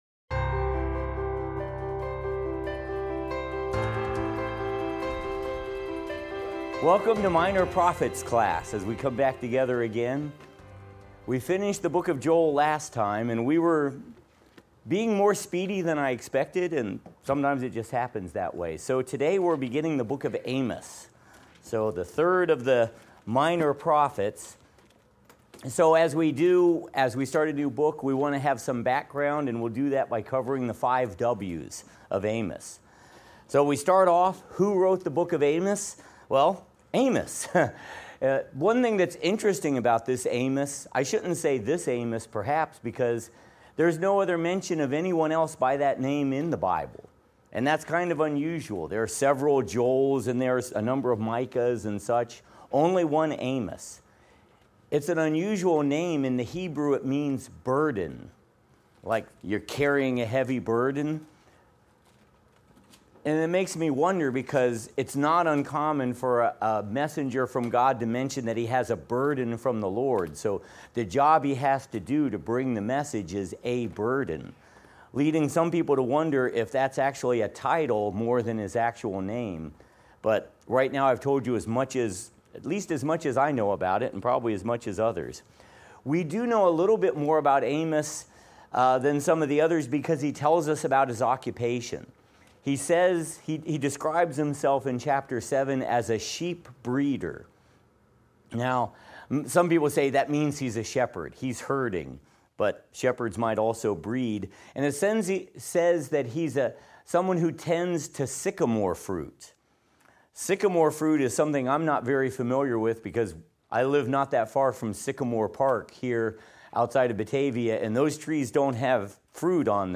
Minor Prophets - Lecture 9 - audio.mp3